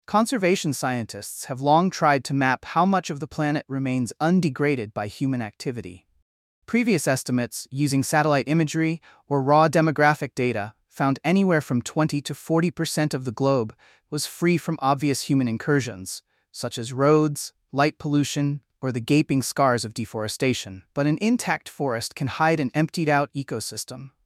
Sample answer: